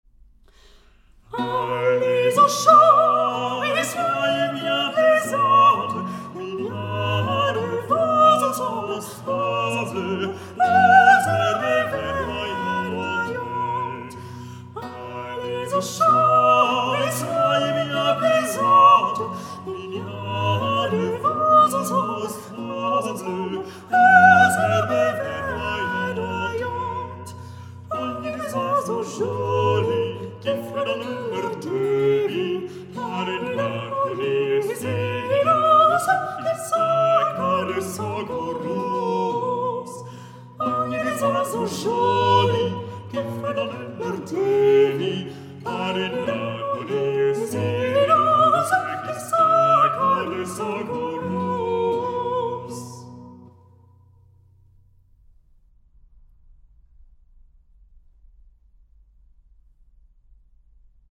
Countertenor
Bariton
Traversflöte
Diskantgambe, Bassgambe
Laute
Kammermusiksaal der hmt Rostock